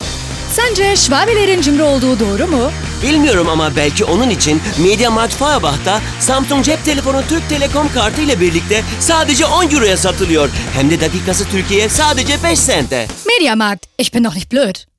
Sprecherin Synchron, Werbung, TV, Kino, Funk, Voice-Over, Höhrbuch, Hörspiel, Online-Games, Native Speaker Deutsch und Türkisch
Sprechprobe: Werbung (Muttersprache):